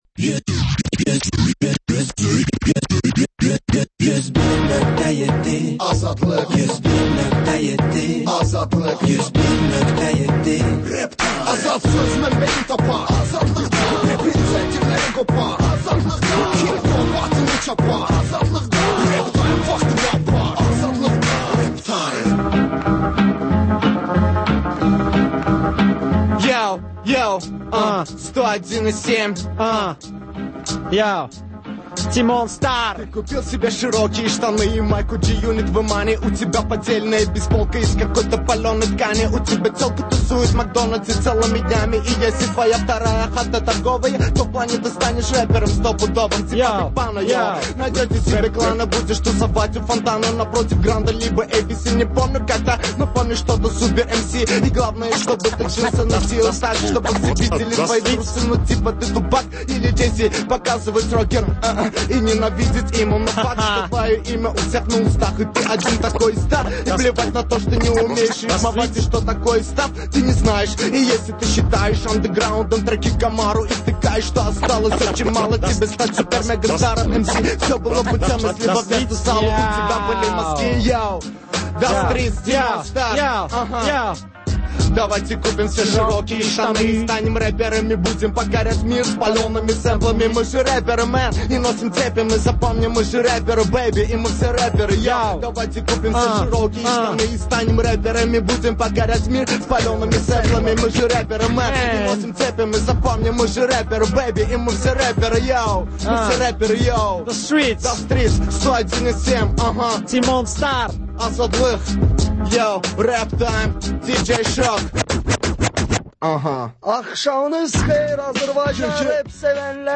Xəbərlər, REP-TIME: Gənclərin musiqi verilişi